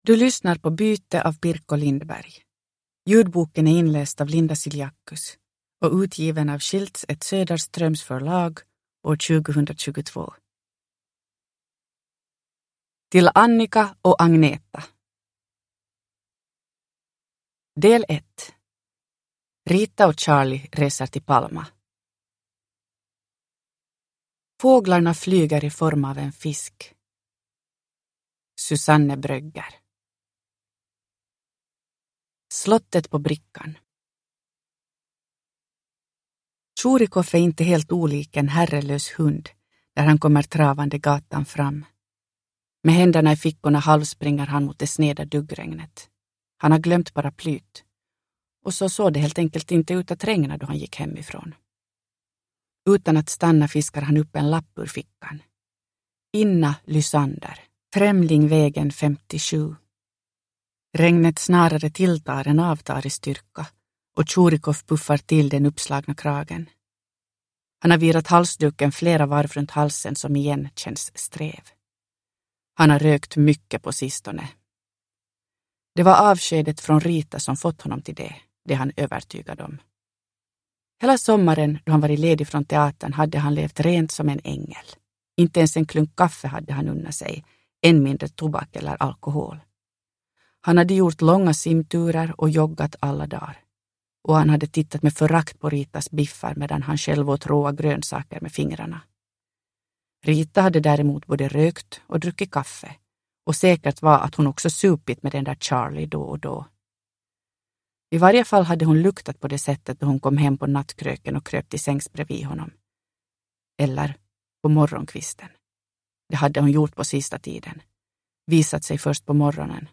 Byte – Ljudbok – Laddas ner